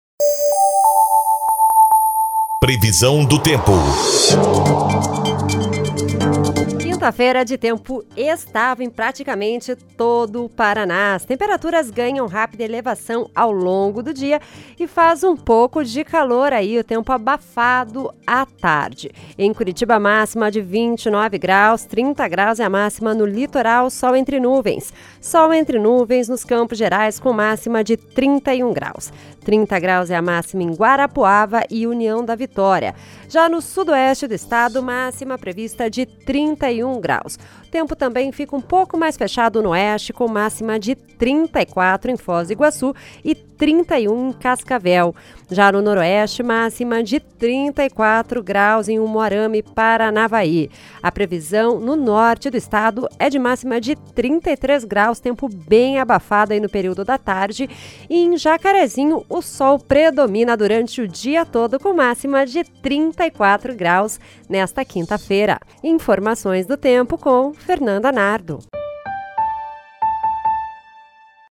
Previsão do Tempo (04/04)